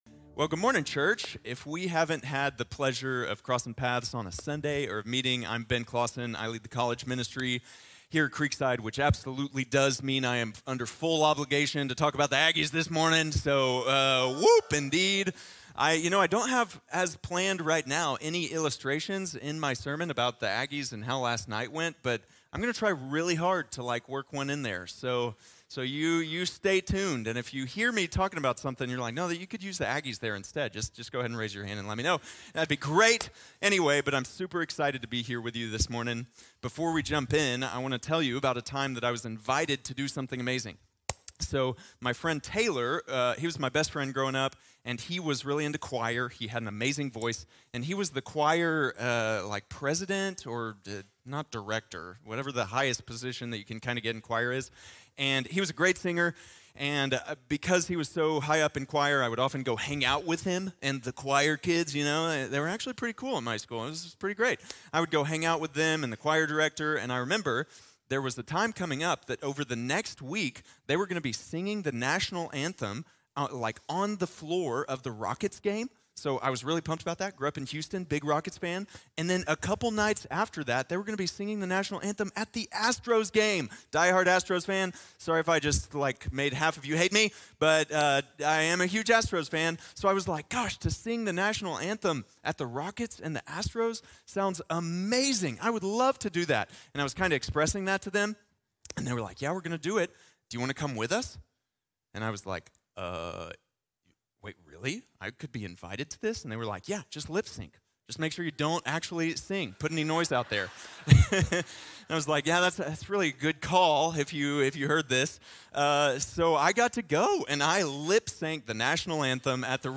A Multiplying Church | Sermon | Grace Bible Church